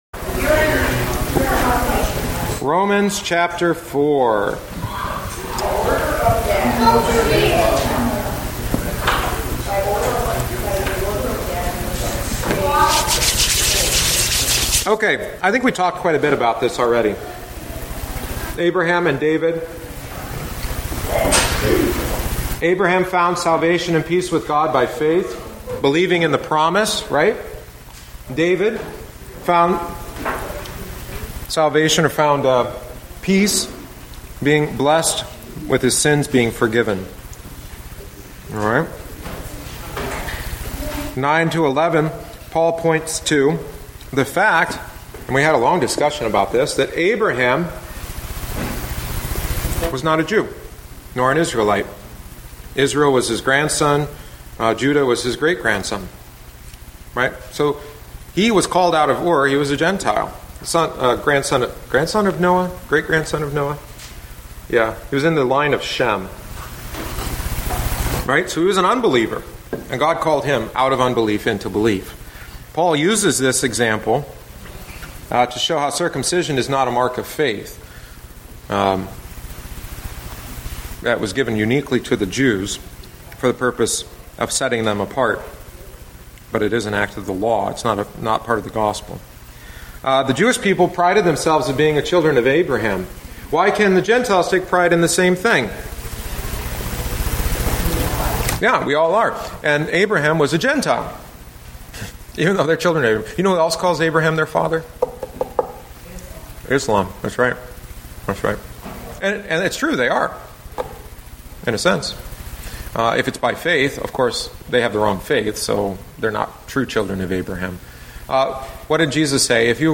The following is the eleventh week’s lesson. Abraham is our pattern; he believed in God, who raises the dead.